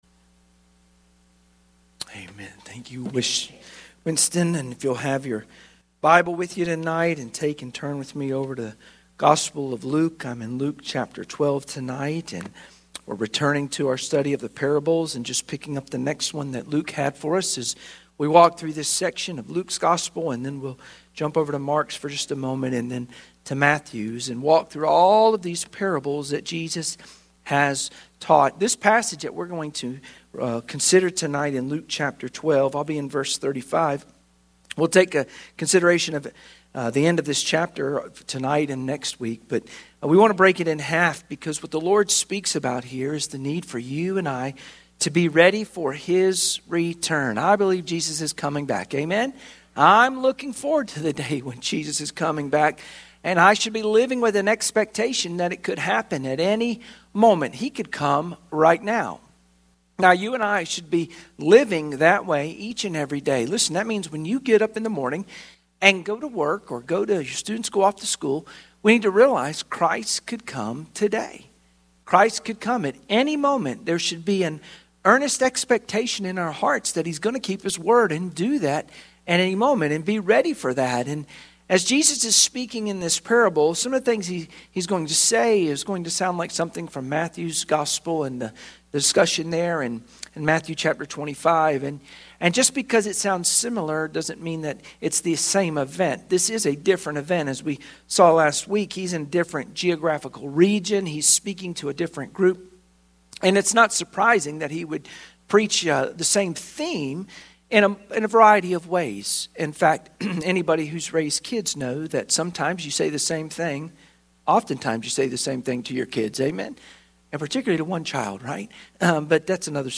Luke 12:34-41 Service Type: Sunday Evening Worship Share this